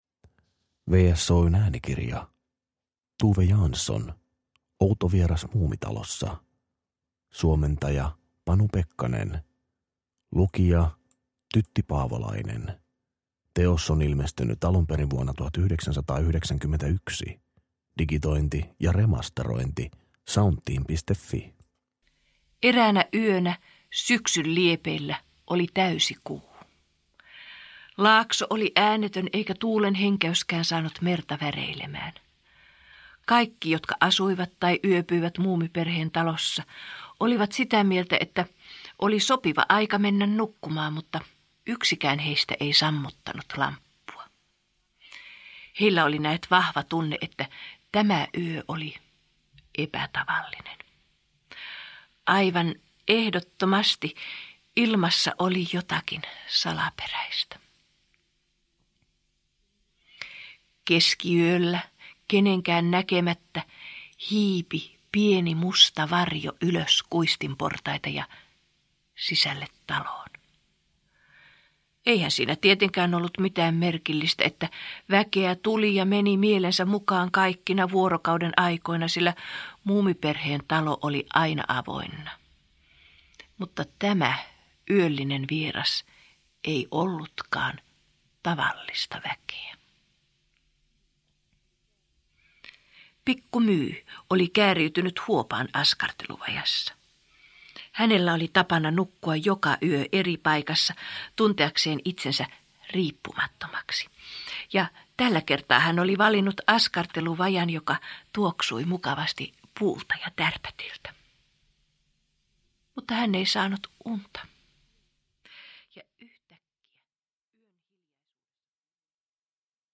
Outo vieras Muumitalossa – Ljudbok – Laddas ner